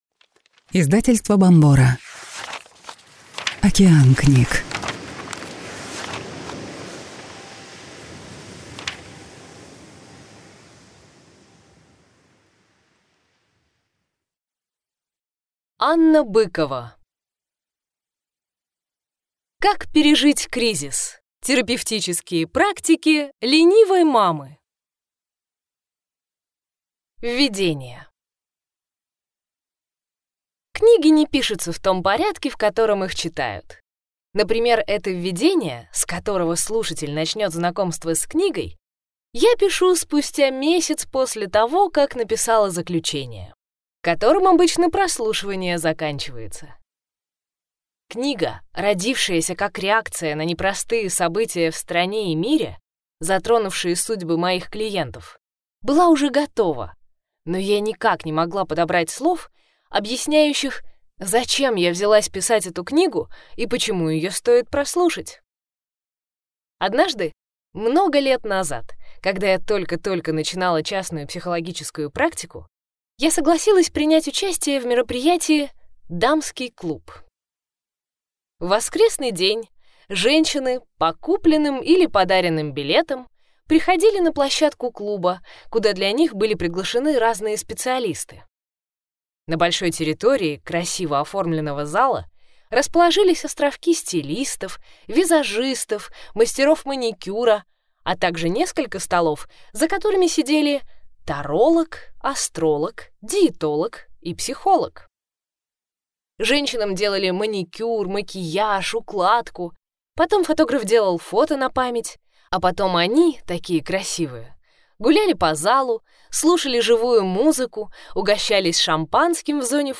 Аудиокнига Как пережить кризис. Терапевтические практики «ленивой мамы» | Библиотека аудиокниг